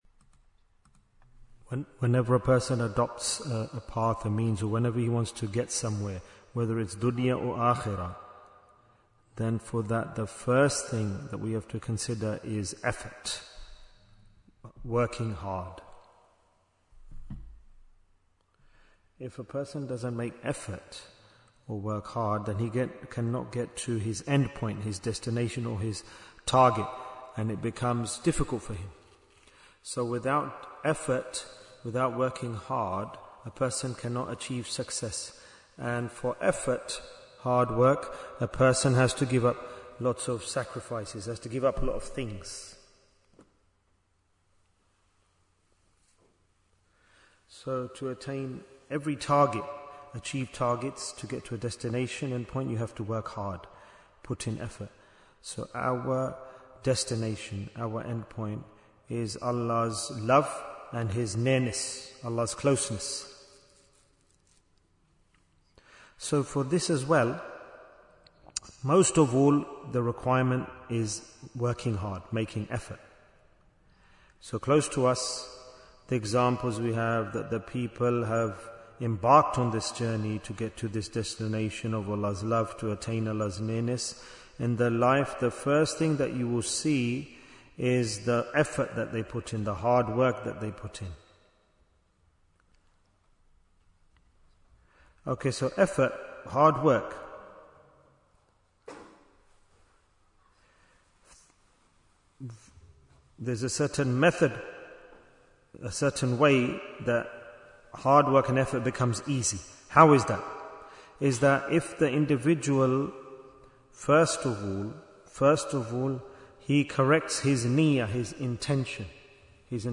Jewels of Ramadhan 2026 - Episode 7 Bayan, 84 minutes21st February, 2026